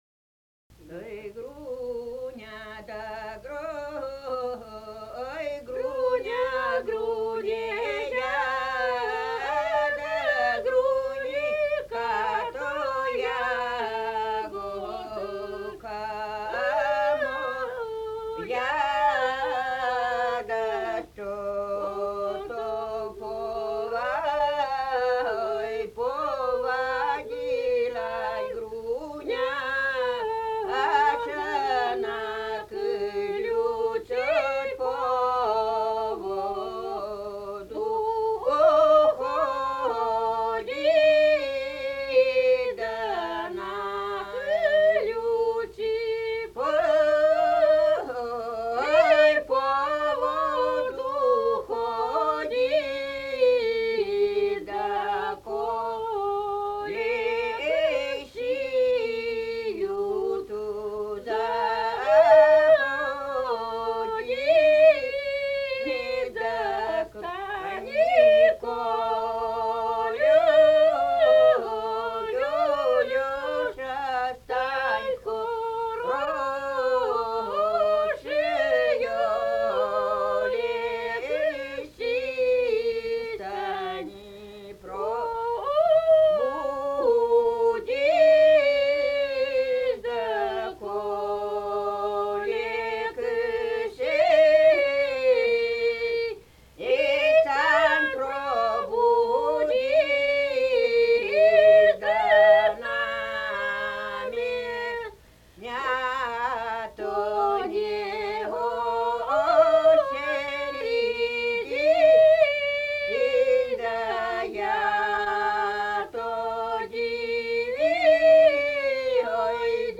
В таких напевах отсутствуют цезуры, в конце строф нет четко выраженного каданса, который плавно «перетекает» в запев следующей строфы.
Например, городок «Груня», записанный в д. Другосимоновская, характеризуется особой двухъярусной формой многоголосия, основанной на октавном удвоении основной мелодической линии, медленным темпом исполнения, обилием слоговых распевов, мелодической насыщенностью, активным использованием мелизматики и микрораспевов.
01 Хороводная песня («городок») «Ой